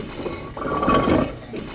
1 channel
spit.wav